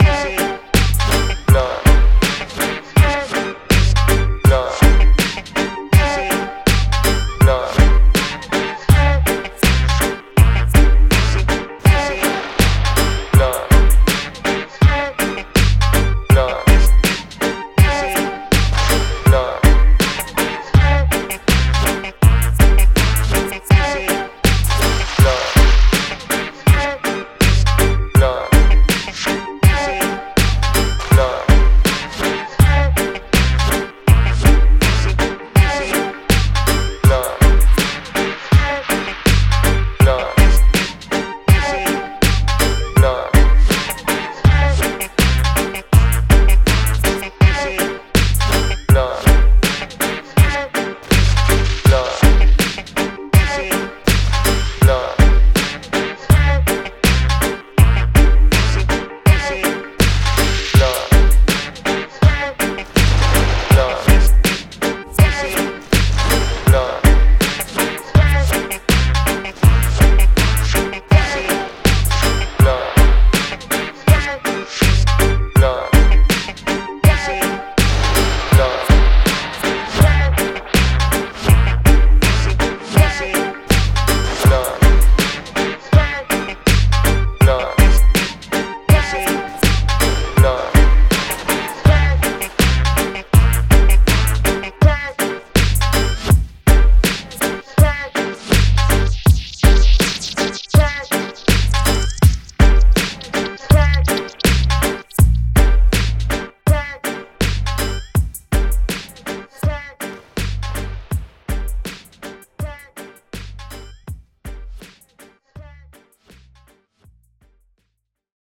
スムーズな81 BPMで構成され、次なるレゲエアンセムを生み出すために必要なすべての要素を詰め込んでいます。
デモサウンドはコチラ↓
Genre:Reggae
Tempo Range: 81bpm
Key: Gm